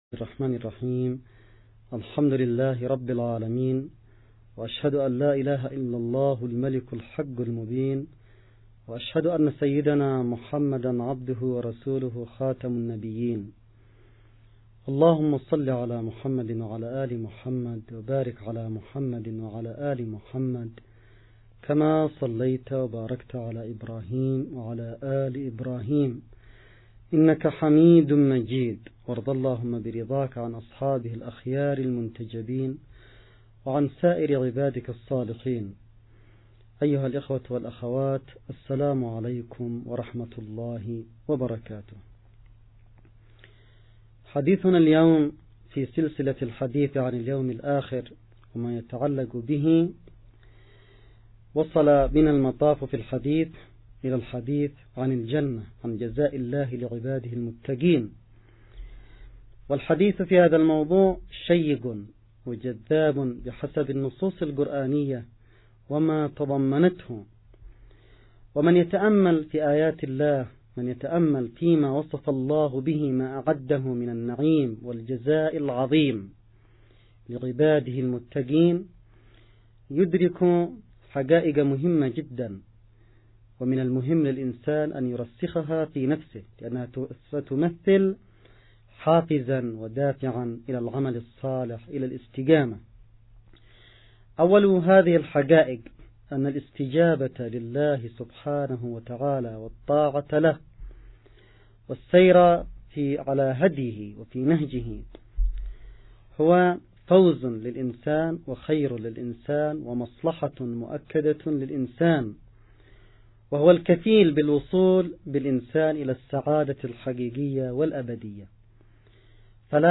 محاضرة-الحساب-والجزاء-8-الجنة.mp3